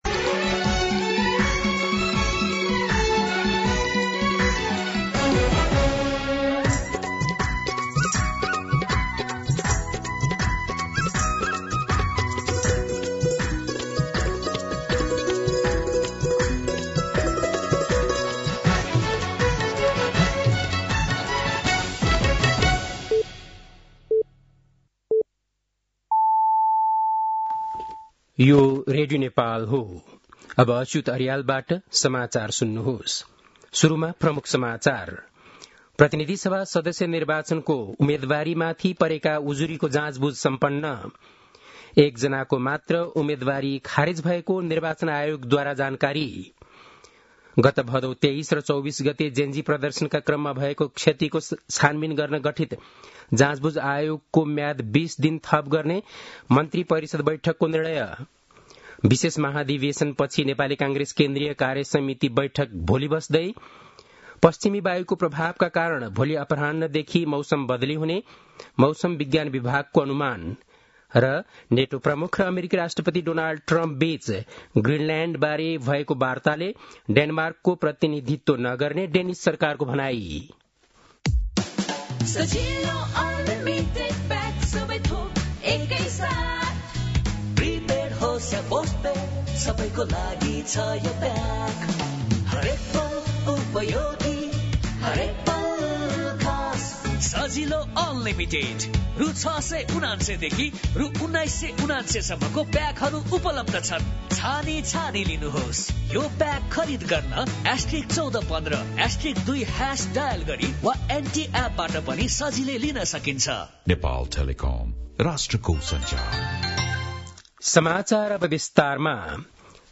An online outlet of Nepal's national radio broadcaster
बेलुकी ७ बजेको नेपाली समाचार : ८ माघ , २०८२
7-pm-nepali-news-1-1.mp3